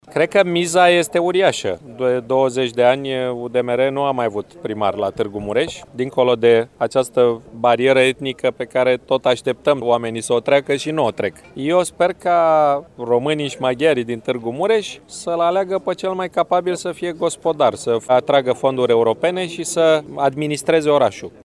În privința scrutinului din 27 septembrie președintele Pro România a declarat: